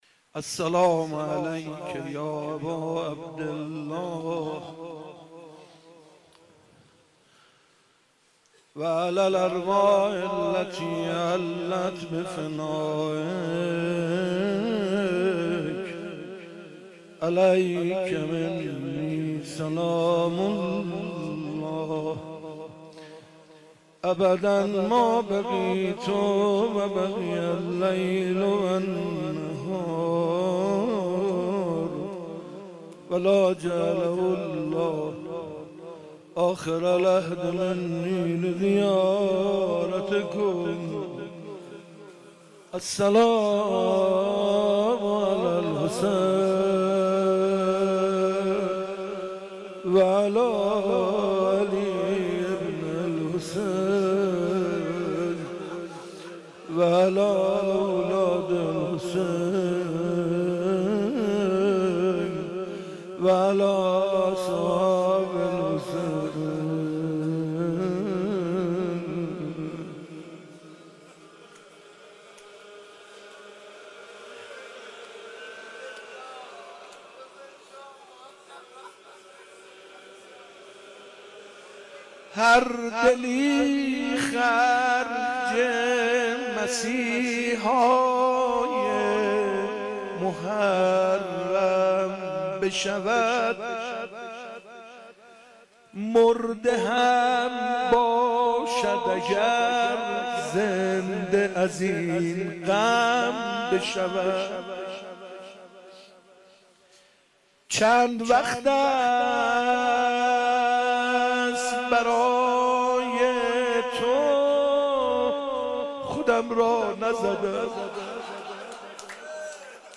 غزل - هر دلی خرج مسیحای محرم بشود